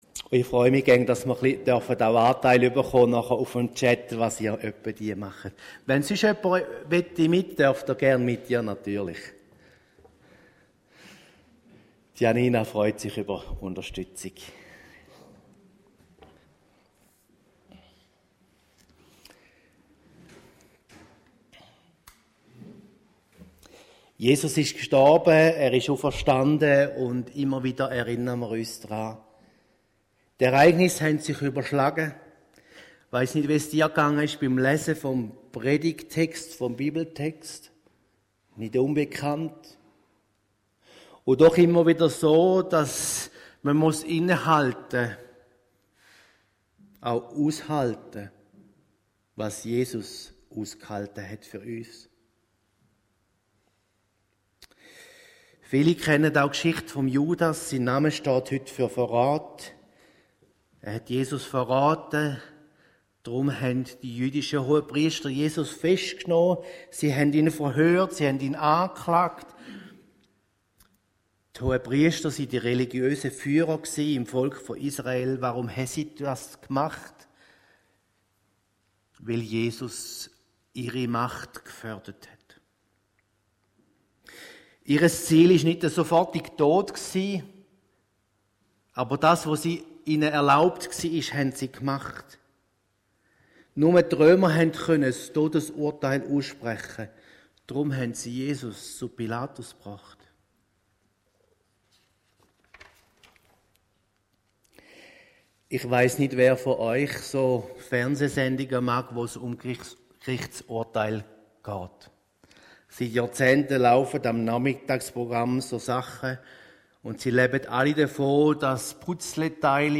Predigten 2026